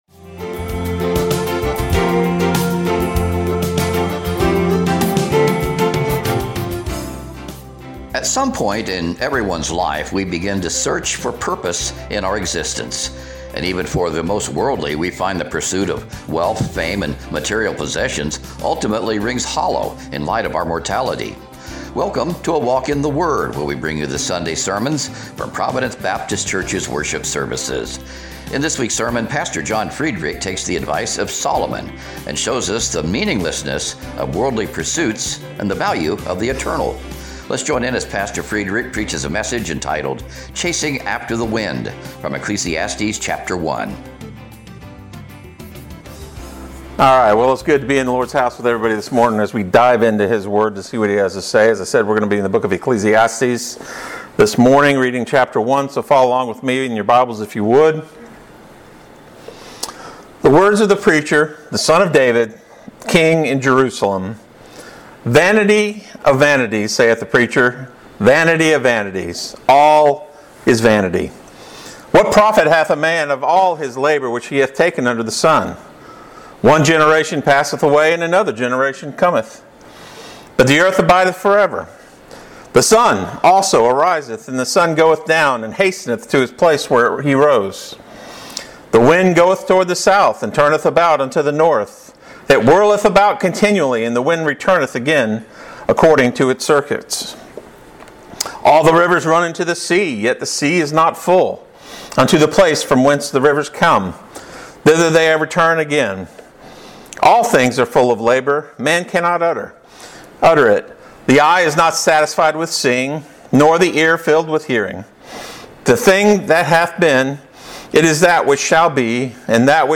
Recent sermons preached at Providence Baptist Church - Gaston